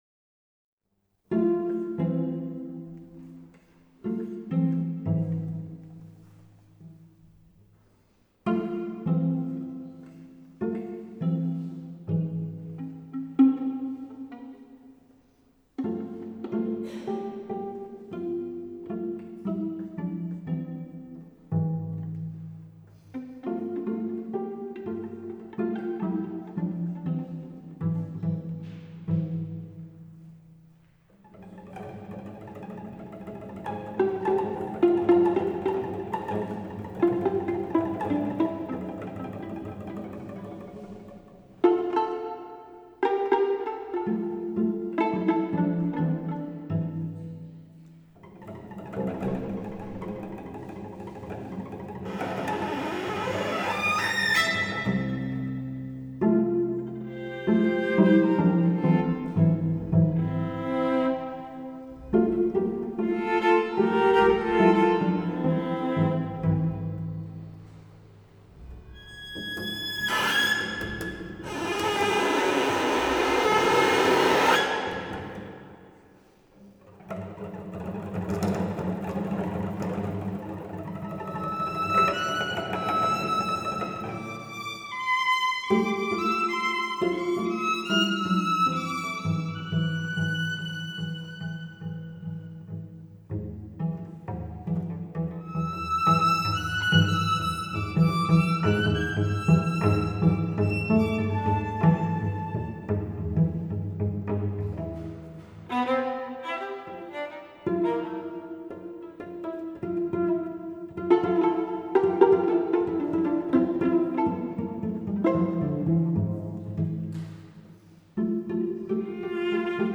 violon
violoncelle